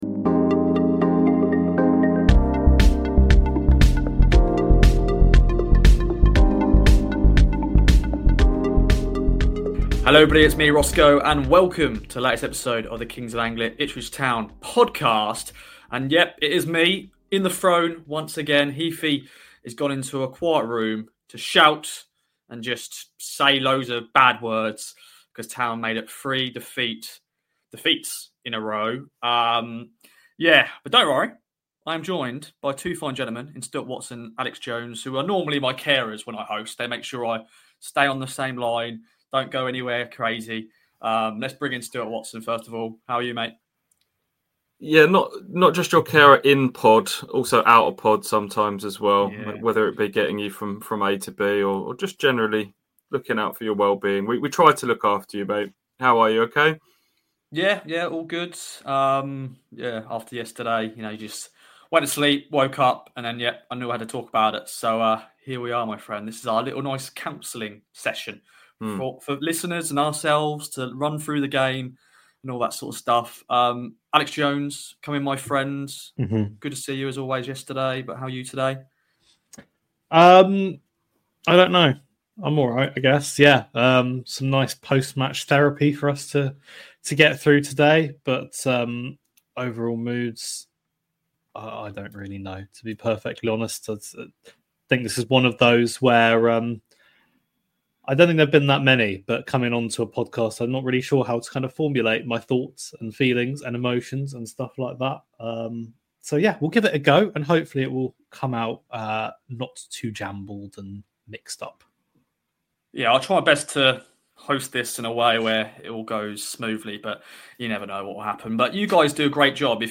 There’s also some chat around the level of the Premier League and FPL, with a wacky doorbell interrupting the pod at the worst time.